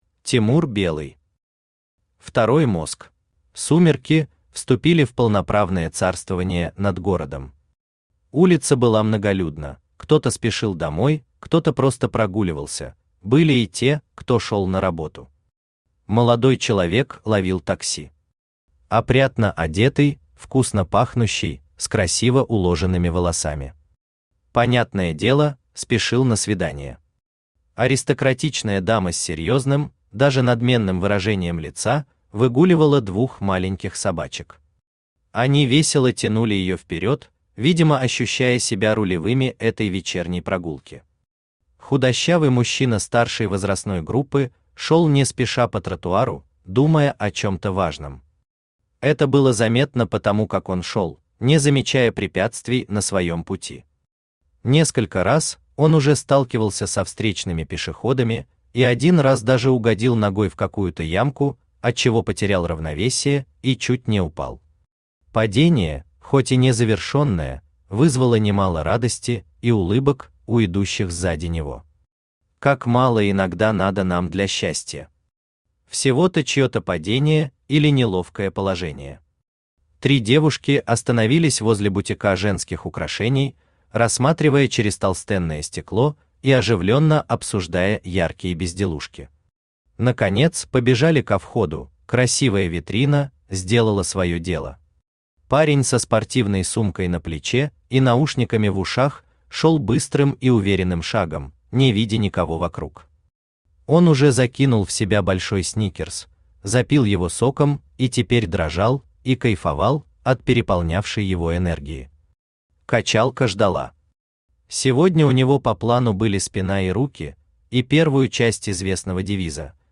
Второй мозг (слушать аудиокнигу бесплатно) - автор Тимур Белый
Читает: Авточтец ЛитРес